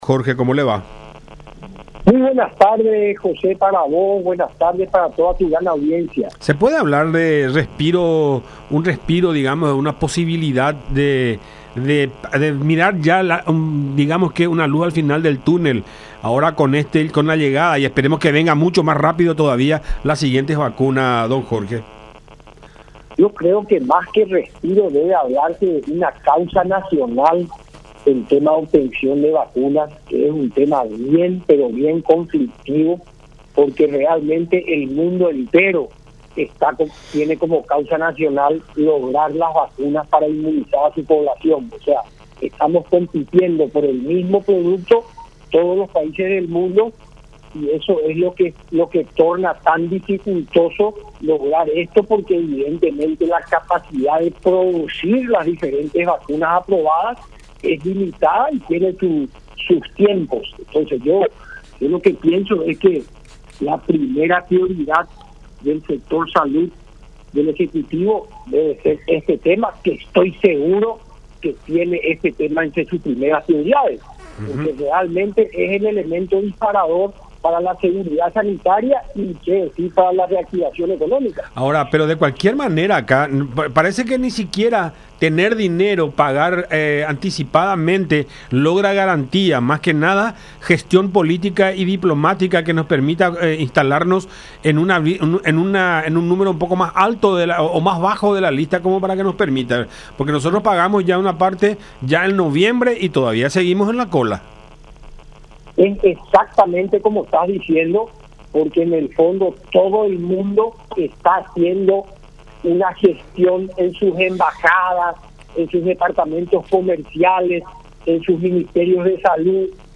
en contacto con La Unión R800AM